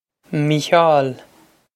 Mícheál Mee-hyahl
Mee-hyahl
This is an approximate phonetic pronunciation of the phrase.